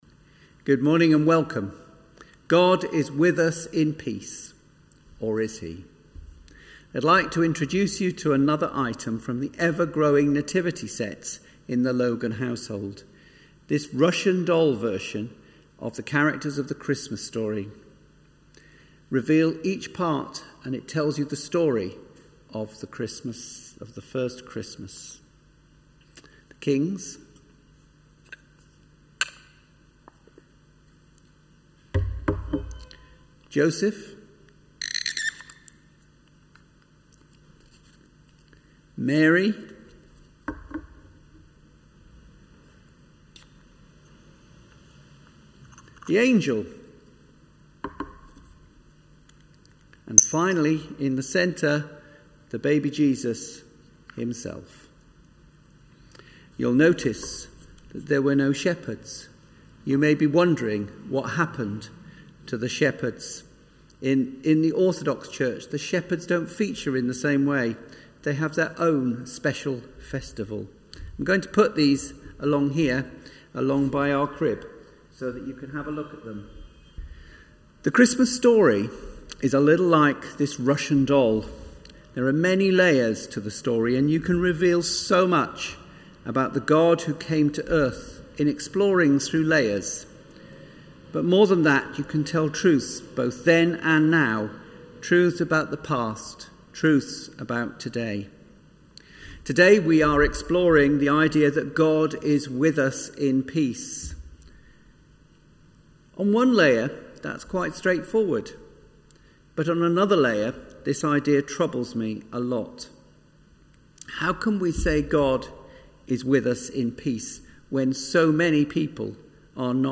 Online Worship Christmas 2020 Isaiah